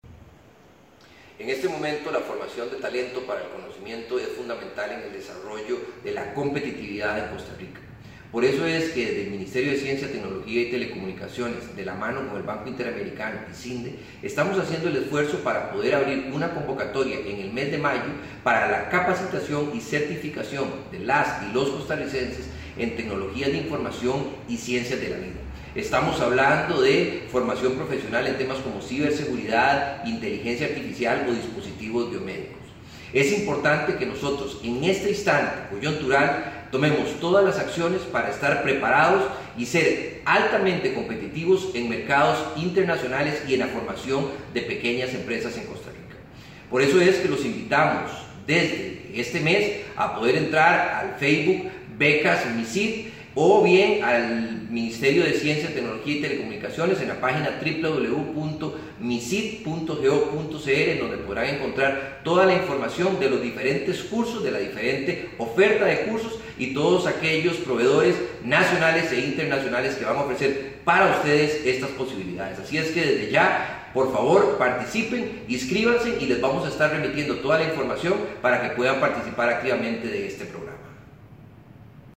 Declaraciones del ministro Luis Adrián Salazar sobre nuevas becas para capacitación y certificación en áreas tecnológicas